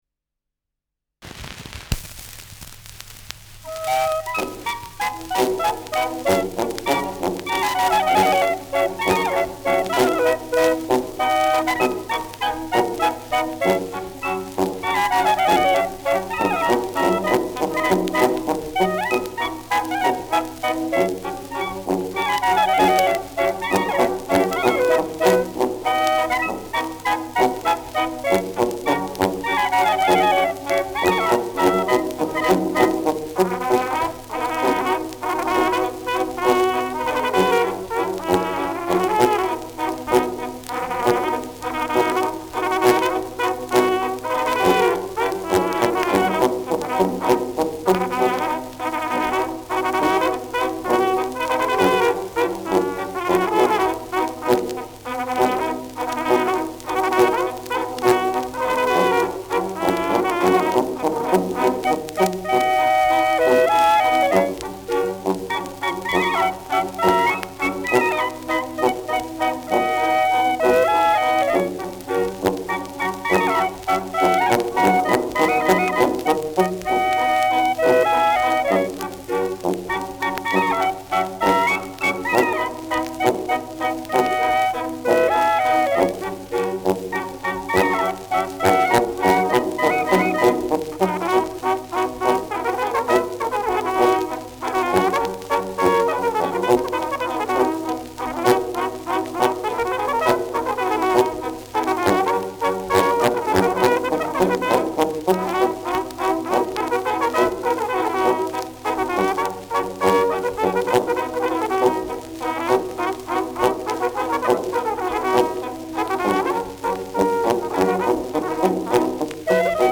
Schellackplatte
Leicht abgespielt : Gelegentlich stärkeres Knacken
Maxglaner Bauernkapelle, Salzburg (Interpretation)